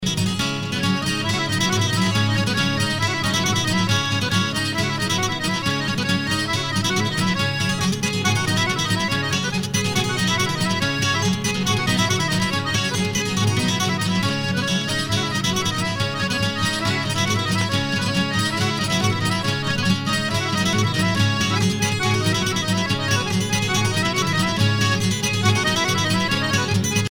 danse : passepied
Pièce musicale éditée